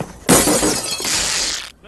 Download Breaking Glass sound effect for free.
Breaking Glass